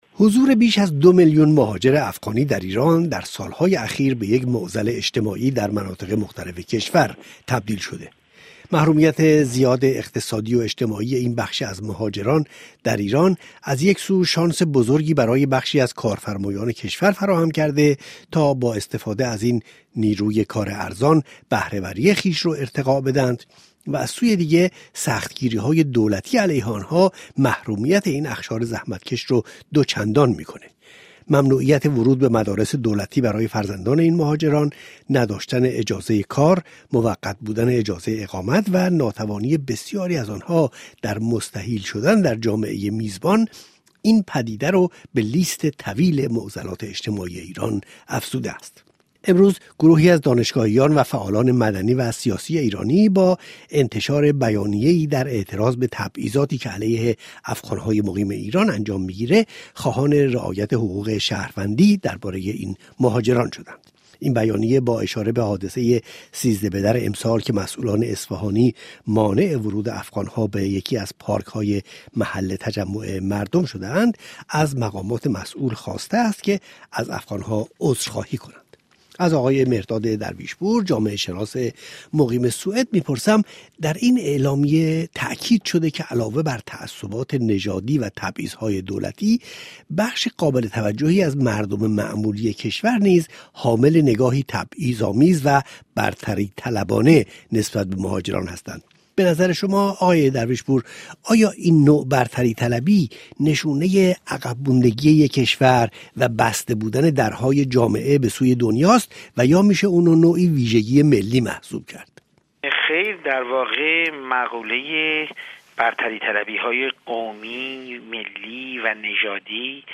گفتگوی رادیو بین المللی فرانسه